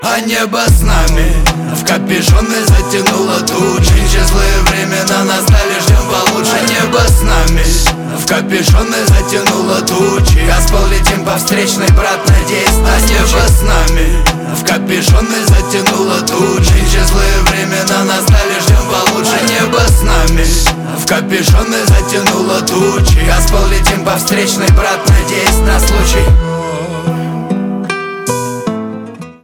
рэп
русский рэп
басы
гитара